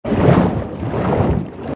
AV_swimming.ogg